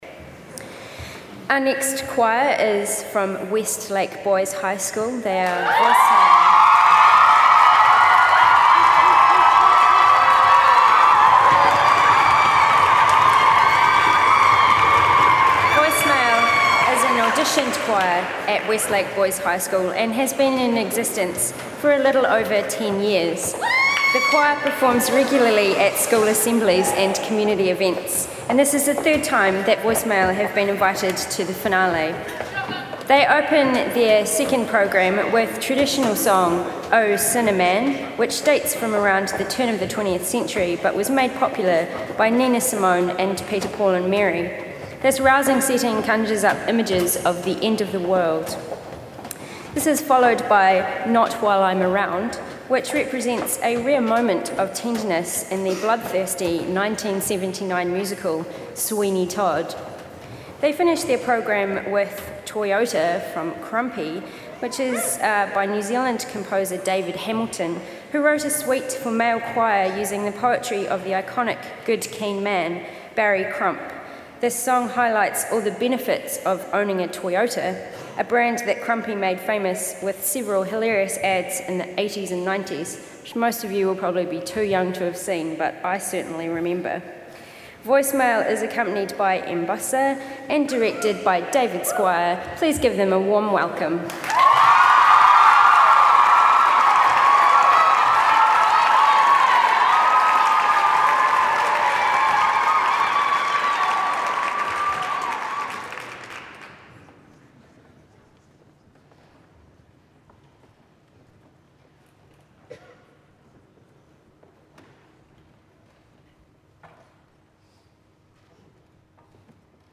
Recordings from The Big Sing National Final.
Voicemale Westlake Boys' High School 1.